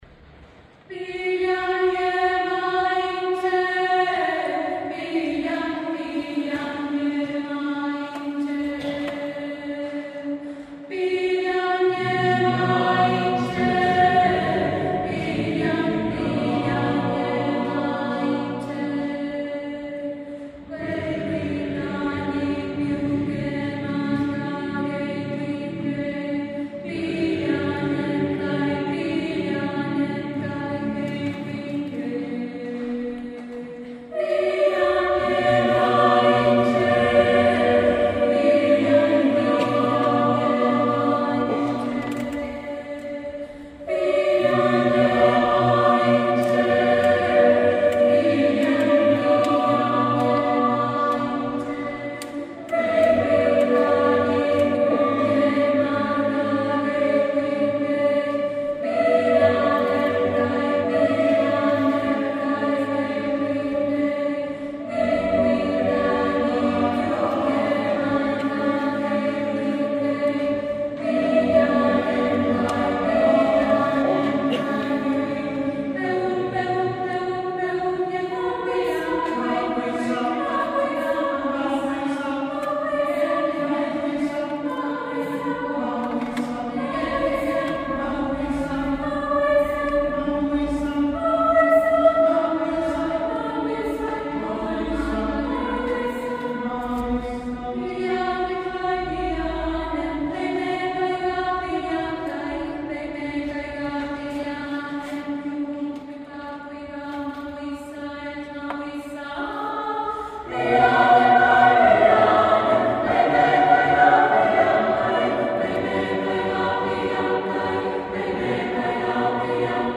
El pasado 3 de diciembre, durante el Concierto de fin de año ofrecido por el Coro Universitario del Comahue y el Coro de Cámara del IUPA en la Catedral de General Roca, tuvieron su estreno las obras corales «Mapu!
Se trata de una obra para 4 voces mixtas compuesta por el compositor Pablo Piccinni, quien se inspiró en el “pillánpurrún”, momento cúlmine del “rinkurrinkupurrún” (baile saltado), en el que los participantes elevan y golpean los talones rítmicamente contra el suelo manteniendo quietas las puntas de los pies.
El 1° Concurso de Composición Coral «Aimé Painé» es una iniciativa interinstitucional impulsada por Delegación Alto Valle de ADICORA y la Mesa Coral Universitaria de Rio Negro y Neuquén, bajo el auspicio de la Secretaría de Extensión de la Universidad Nacional del Comahue, la Facultad de Lenguas de la UNCo, el Instituto Universitario Patagónico de las Artes y la ADICORA Nacional, y tiene por objeto la promoción de las lenguas y las culturas originarias a través de la creación de piezas inéditas a capela.